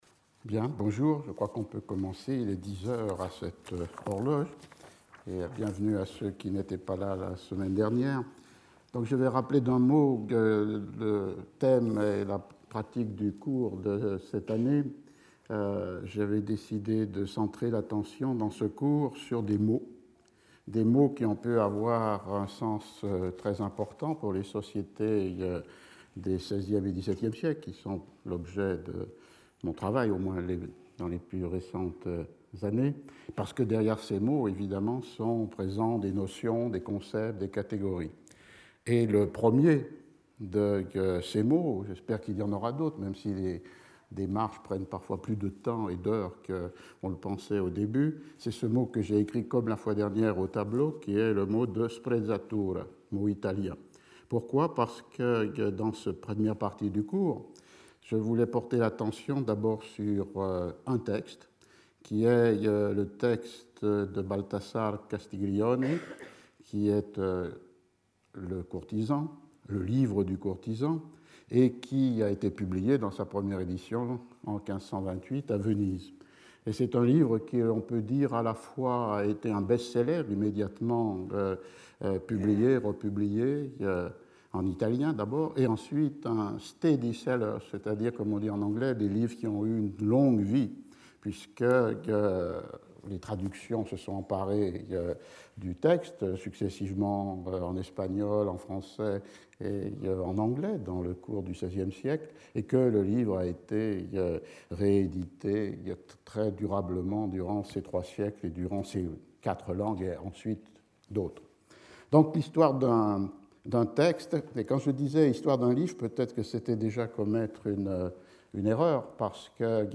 Speaker(s) Roger Chartier Professor at the Collège de France
Lecture